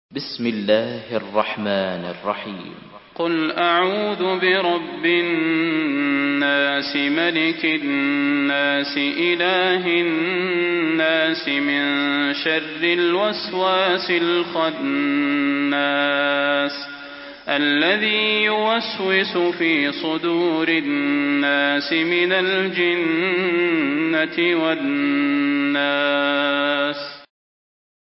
Surah An-Nas MP3 in the Voice of Salah Al Budair in Hafs Narration
Murattal Hafs An Asim